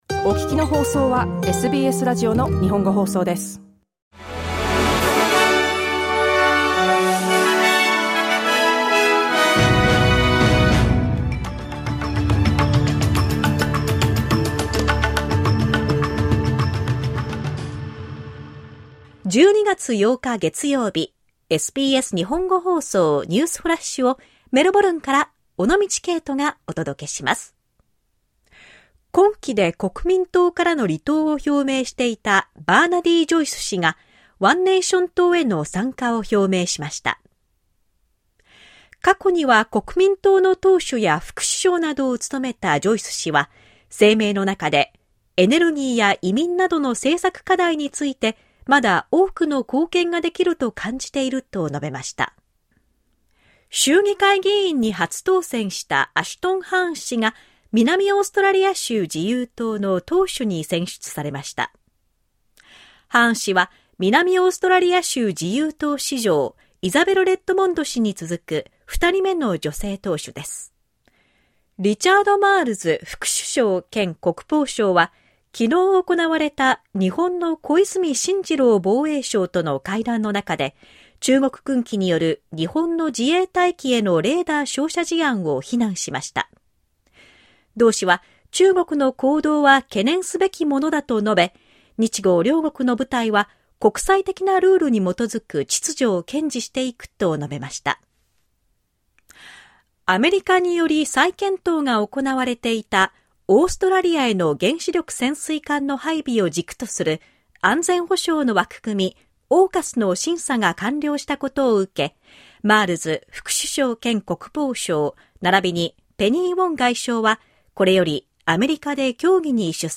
SBS日本語放送ニュースフラッシュ 12月8日 月曜日